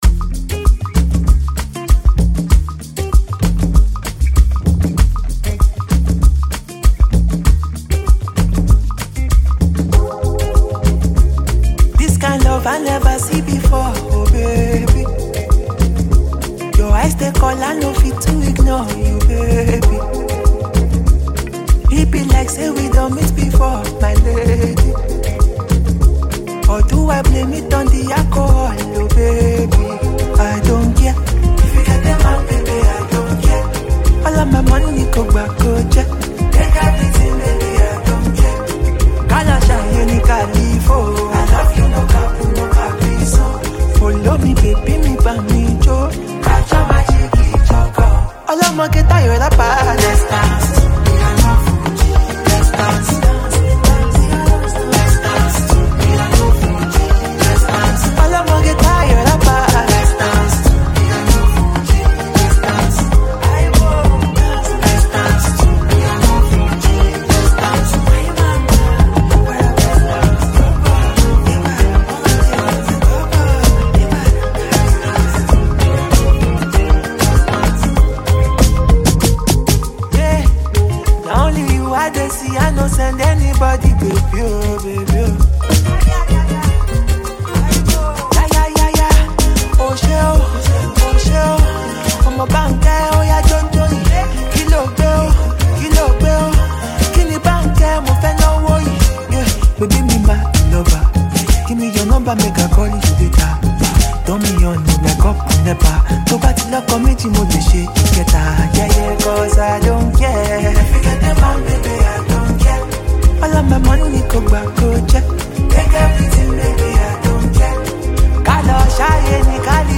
• Genre: Afrobeats